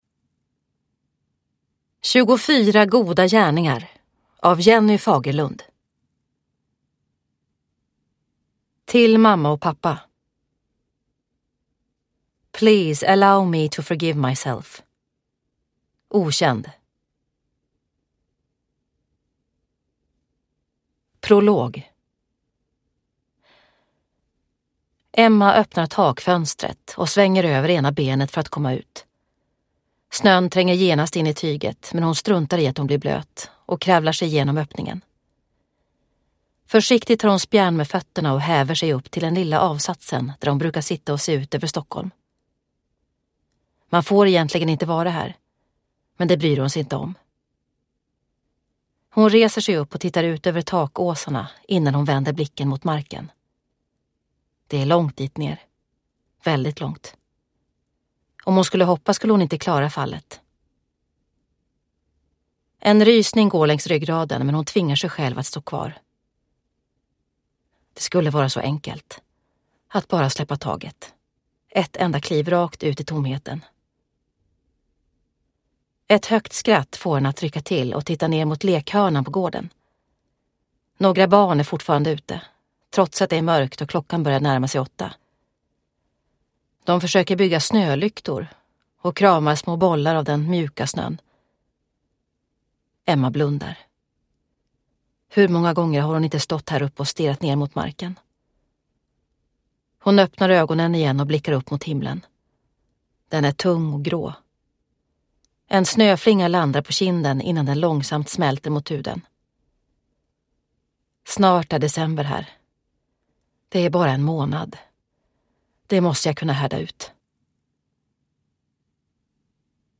24 goda gärningar – Ljudbok – Laddas ner